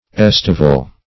Estival \Es"ti*val\, a., Estivate \Es"ti*vate\, v. i.,